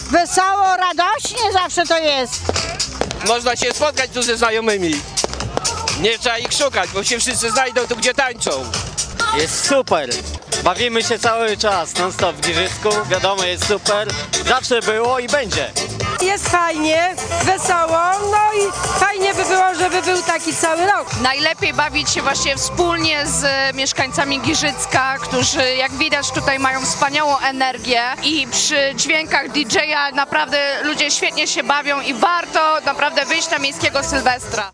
Zabawa sylwestrowa „pod chmurką” to dobry pomysł na powitanie Nowego Roku- mówili uczestnicy imprezy.